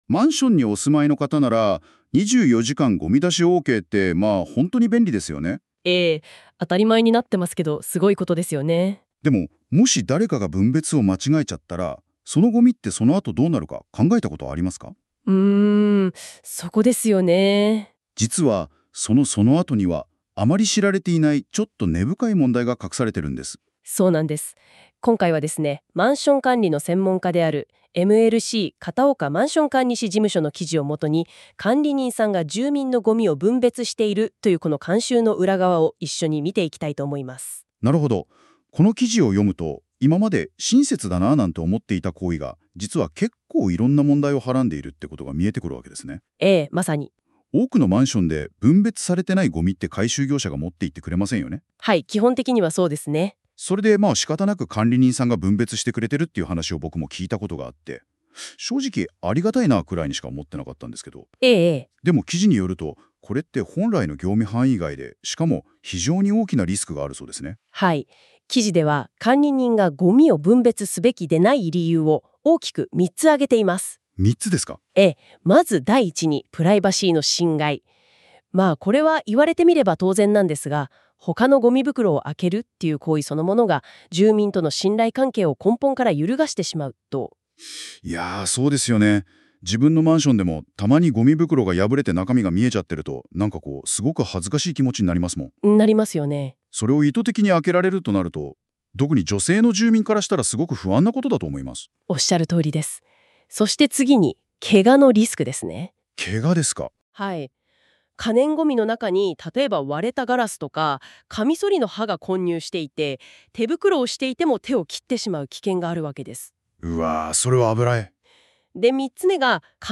🎧 音声解説（約5分）